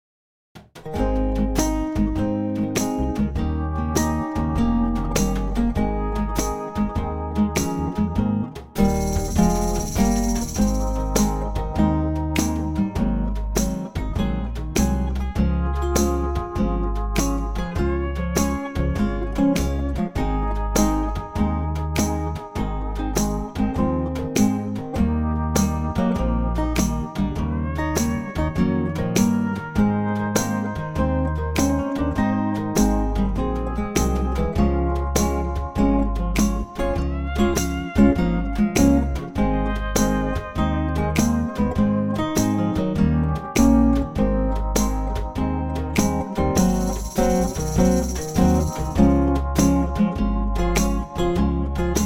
key - Ab - vocal range - Eb to Ab
A superb acoustic arrangement